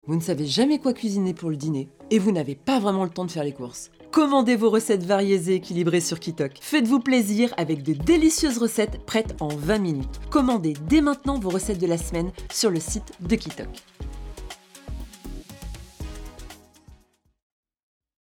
Voix off
35 - 40 ans - Mezzo-soprano